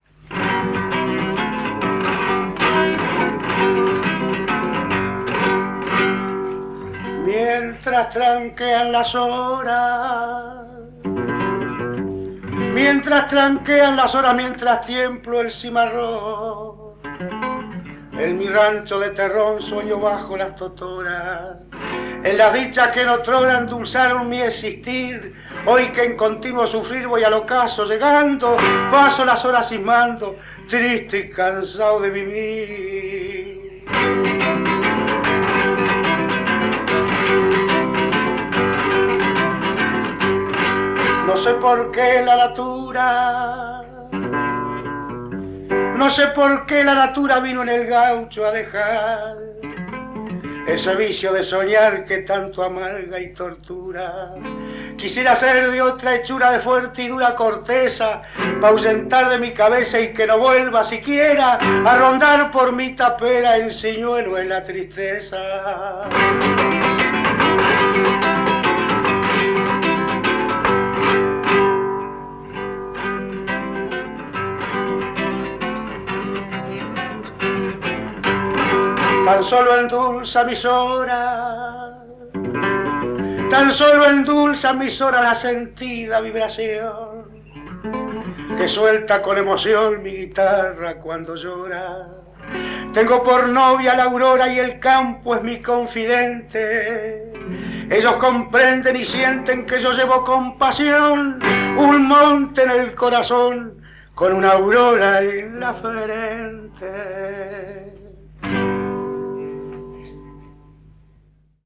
Especie: cifra
canto y guitarra
Formato original de la grabación: cinta magnética a 19 cm/s